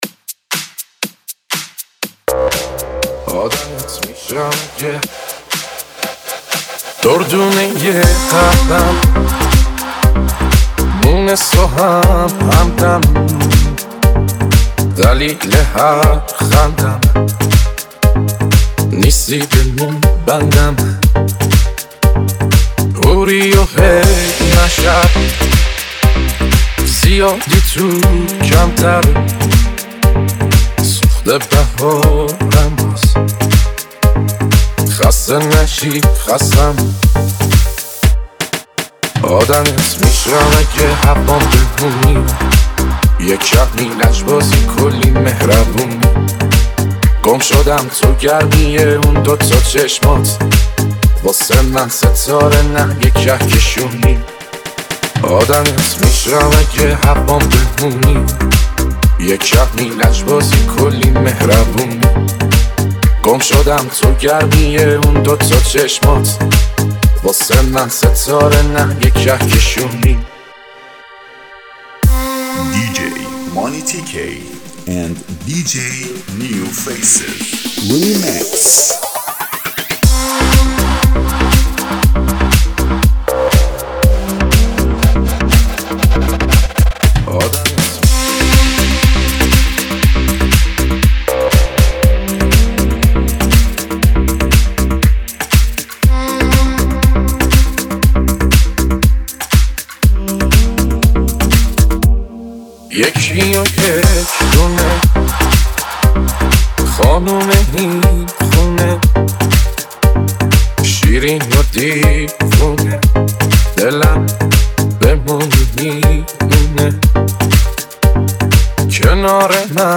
بیس دار